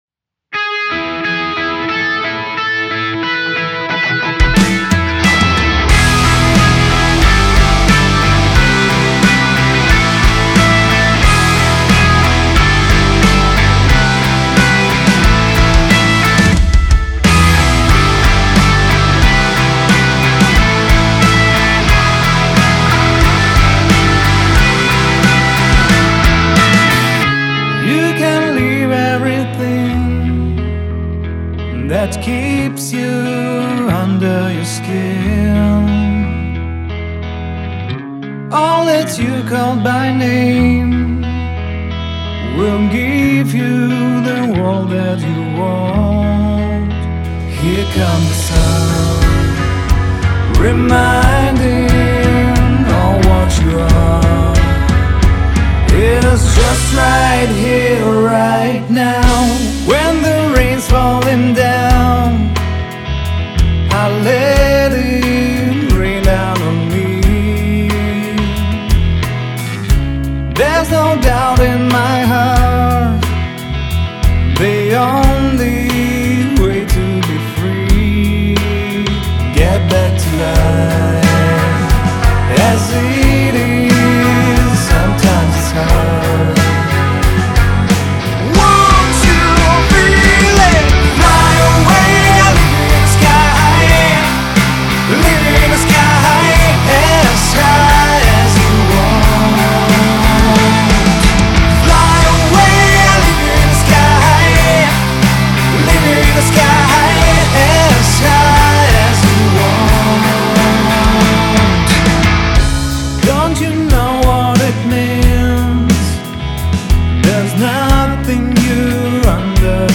Гитары HG- это дублированный дабл трек,один из них Neural DSP Soldano, другой Gogira ,но у последнего каб симулятор от GDD Zilla ,не сам плагин GDD,а его импульс через NadIR.
Гитара Интро- это Plini ,куплетная гитара Soldano .Соло партия -это кетайский педал Hotone Ampero one(импульс Fender Twin). Бас гитара через UAD Ampeg SVTR. Ну..., везде EQ и comp всяческие Это моя первая попытка собрать песню на новой DAW Studio ONE, со старой Samplitude случился скандал и развод..все деньги которые она у меня забрала ..оставил ей и её детям.